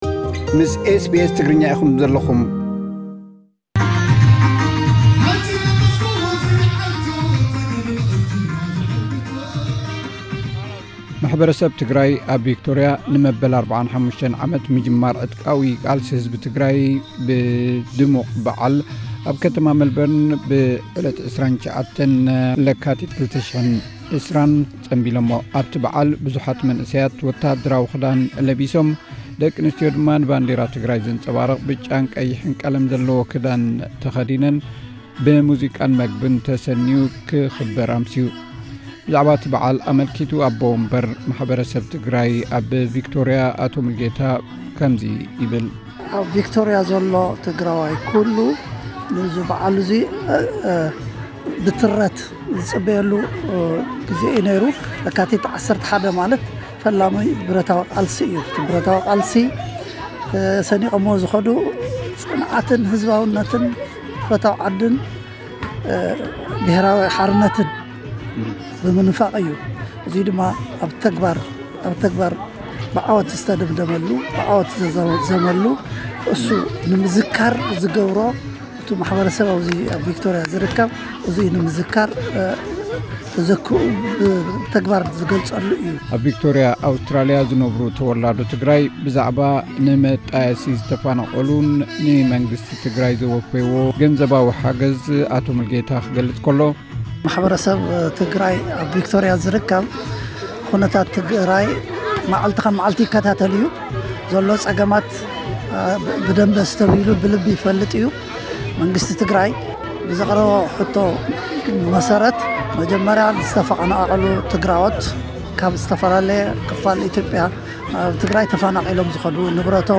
ኣብ ቪክቶሪያ ዝነብሩ ማሕበረሰብ ትግራይ ከምቲ ኣብ ምሉእ ዓለም ክግበር ዝወረሐ በዓል ዝኽሪ መበል 45 ምጅማር ህወሓት ብ 29 ለካቲት ኣብ መልበርን ብዝተፈላለዩ መደባት ዘኪሮሞ። ነዚ ዝምልከት ሓጺር ጸብጻብ።